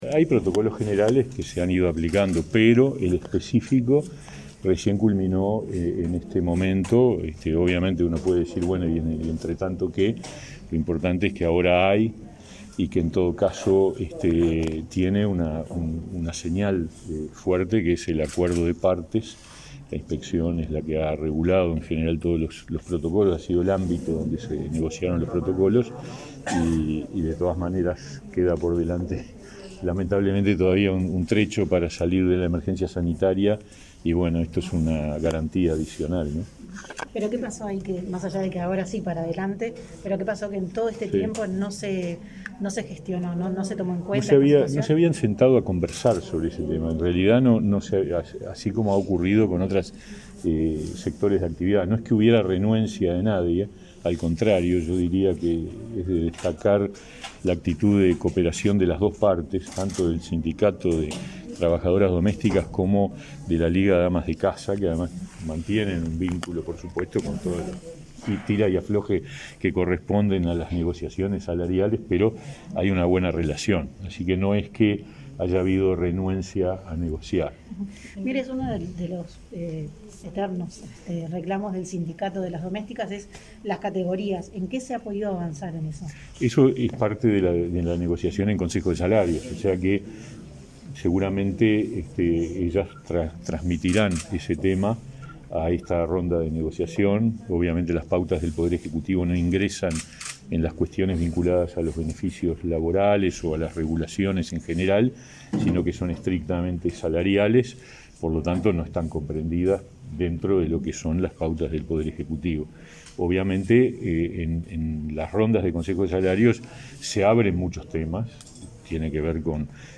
Declaraciones a la prensa del ministro de Trabajo y Seguridad Social, Pablo Mieres
Declaraciones a la prensa del ministro de Trabajo y Seguridad Social, Pablo Mieres 19/08/2021 Compartir Facebook X Copiar enlace WhatsApp LinkedIn Tras finalizar el acto por el Día de las Trabajadoras Domésticas, el 19 de agosto, en la sede del Ministerio de Trabajo y Seguridad Social, el jerarca brindó declaraciones a la prensa.